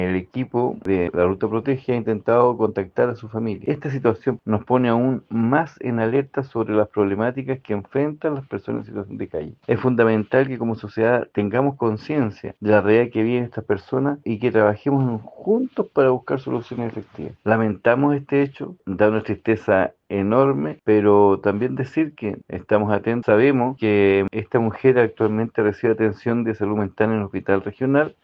Cabe mencionar que, la mujer se encontraba recibiendo atención de salud mental en el Hospital Regional y también tenía un consumo problemático de múltiples drogas, según confirmó el seremi de Desarrollo Social, Enzo Jaramillo, quien manifestó su preocupación por el aumento de la circulación de drogas en la zona.